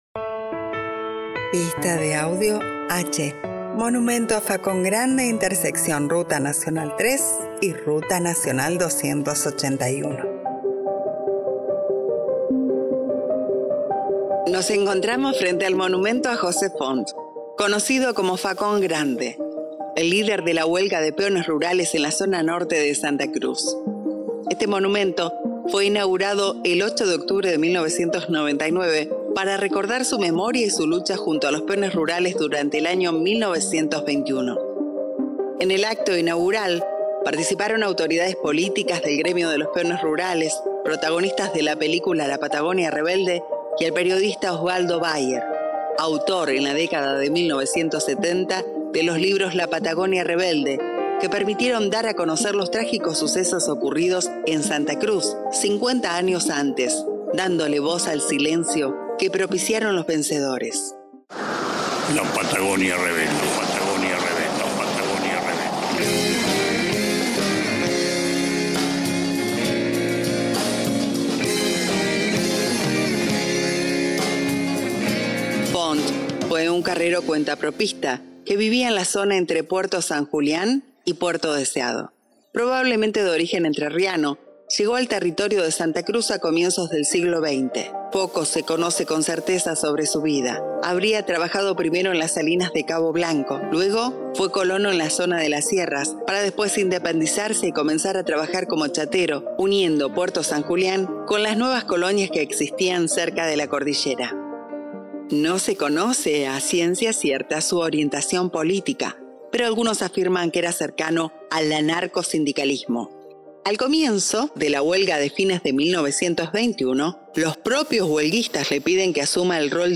Audioguía Vehicular Huelgas Patagónicas